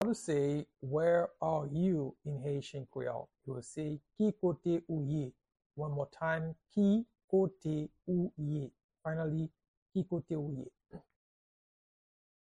How-to-say-Where-are-you-in-Haitian-Creole-–-Ki-kote-ou-ye-pronunciation-by-a-Haitian-teacher.mp3